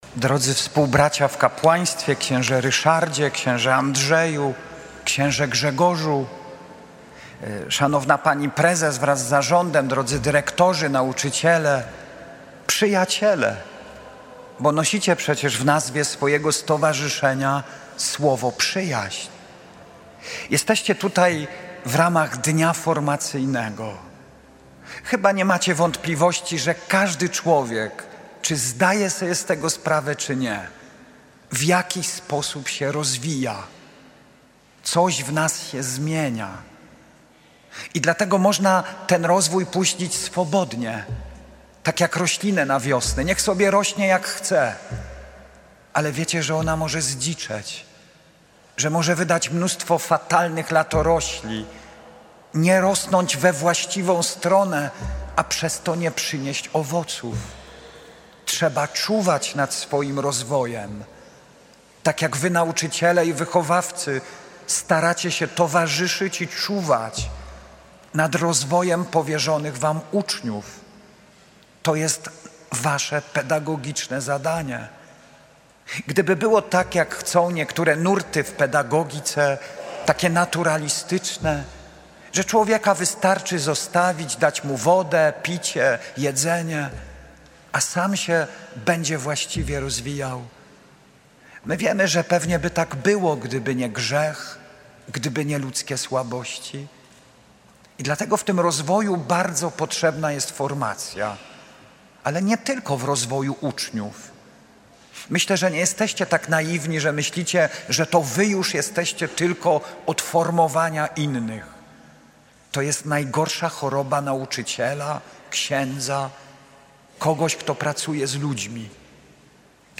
Spotkanie miało charakter formacyjny – rozpoczęło się Mszą św. o godz. 10.00 pod przewodnictwem JE ks. biskupa pomocniczego diecezji Częstochowskiej Andrzeja Przybylskiego. Ks.Biskup w czasie kazania przybliżył istotę powołania nauczycielskiego – ojcostwo i macierzyństwo – prawdziwa pomoc rodzinie.
Nagranie z Radia Jasna Góra